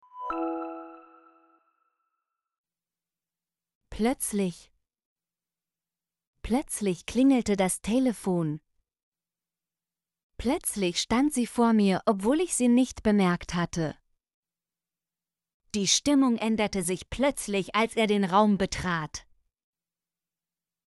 plötzlich - Example Sentences & Pronunciation, German Frequency List